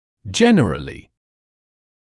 [‘ʤenərəlɪ][‘джэнэрэли]в целом; вообще; в большинстве случаев